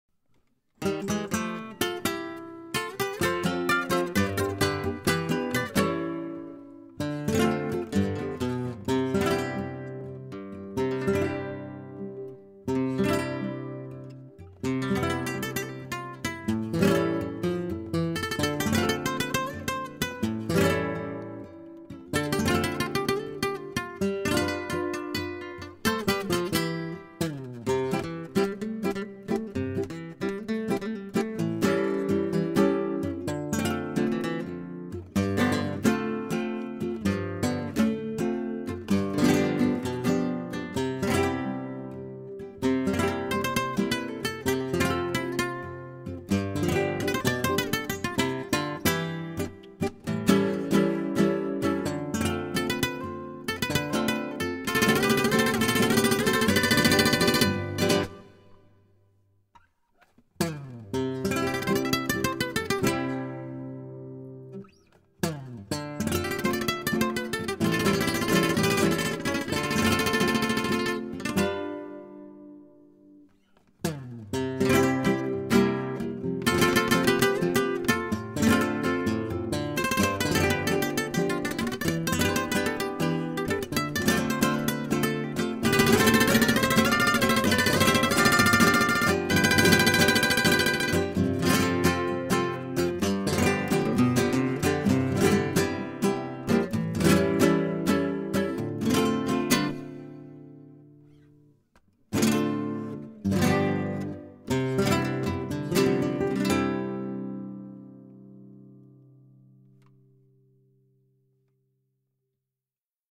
LBAs2Q4fTiR_Pasodoble-Instrumental-Bonus-Track-320-kbps-.mp3